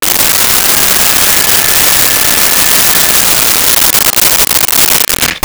Blender On Mix
Blender on Mix.wav